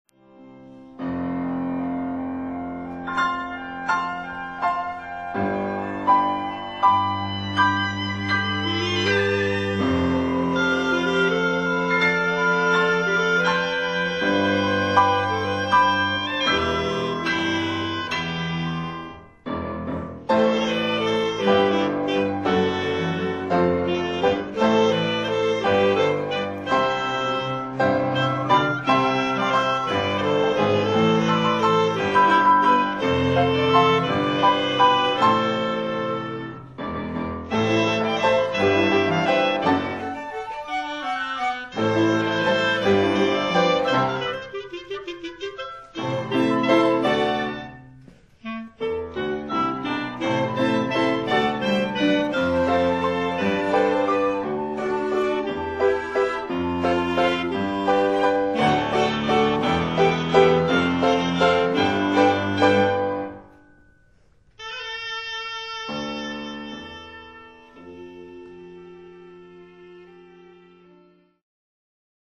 音楽ファイルは WMA 32 Kbps モノラルです。
Flute、Oboe、Clarinet、Violin、Cello、Piano
（299,056 bytes） 不思議な空間へいざない漂わせる変拍子曲。